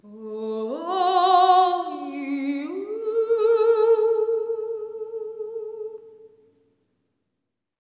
ETHEREAL03-R.wav